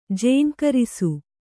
♪ jēŋkarisu